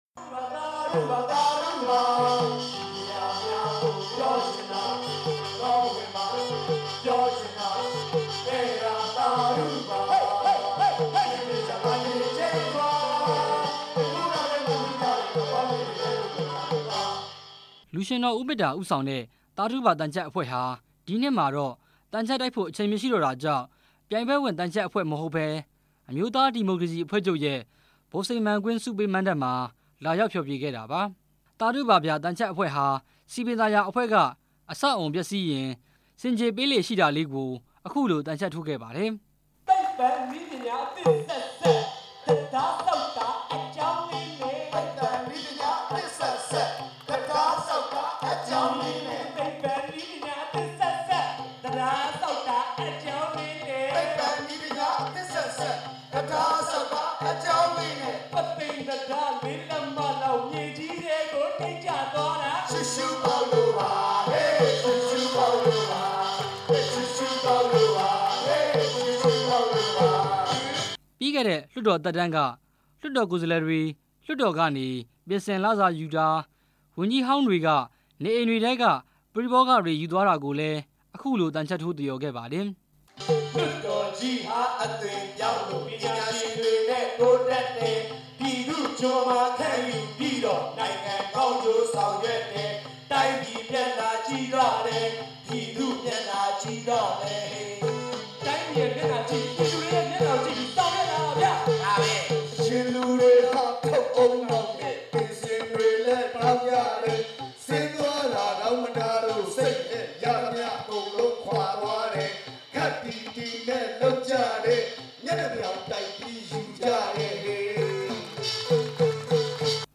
ရန်ကုန်တိုင်းဒေသကြီး ဗဟန်းမြို့နယ် အမျိုးသားဒီမိုကရေစီအဖွဲ့ချုပ် မဟာသြင်္ကန် ဆုပေးမဏ္ဍပ်မှာ